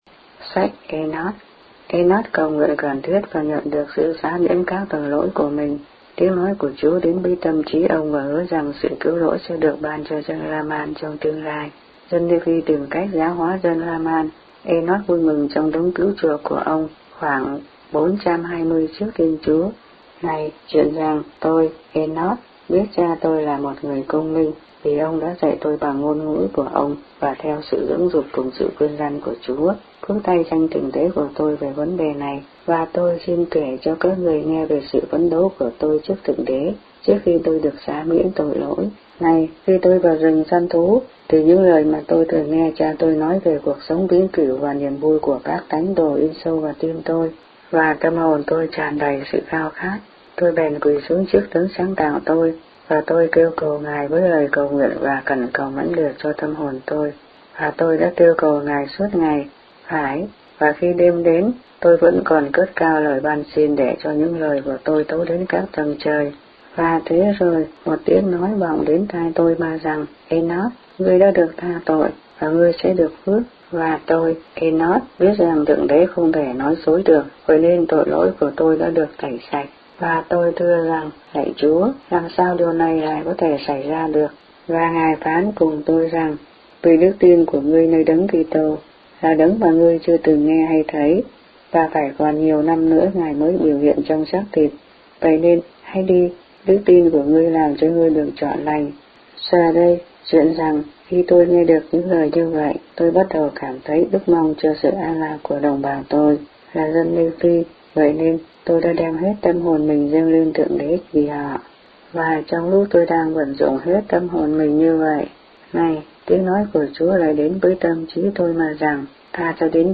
The Book of Mormon read aloud in Vietnamese.